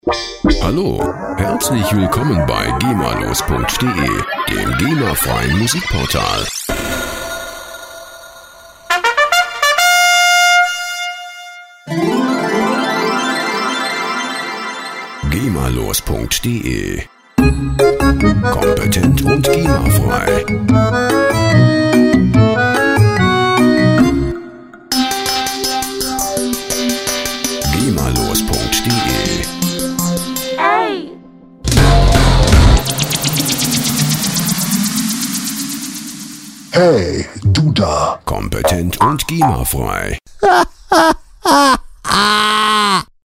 rechtefreie Audio Logos
Musikstil: 12 Klingeltöne
Tempo: kein